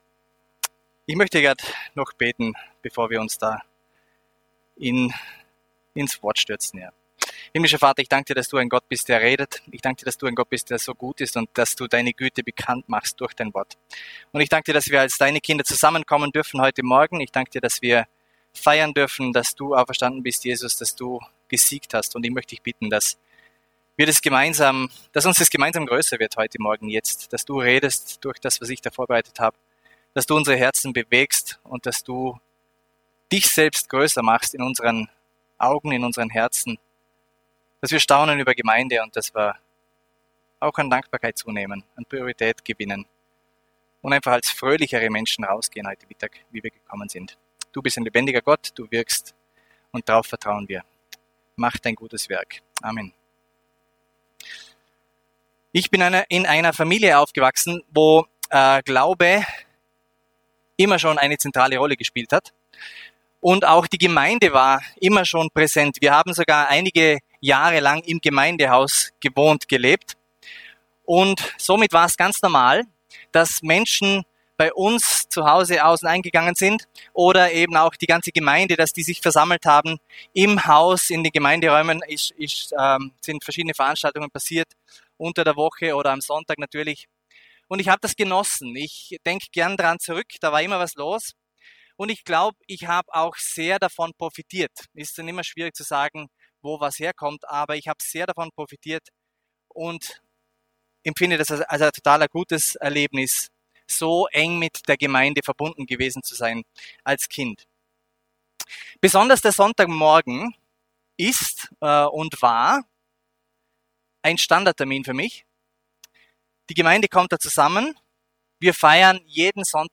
Das Apostolische Glaubensbekenntnis ist so eine Zusammenfassung „bodenständigen“ Glaubens. In zehn Predigten widmen wir uns diesen Wahrheiten mit Hilfe von Gottes Wort und bekommen festen Boden unter die Füße in Zeiten, wo so vieles sonst ins Wanken gerät.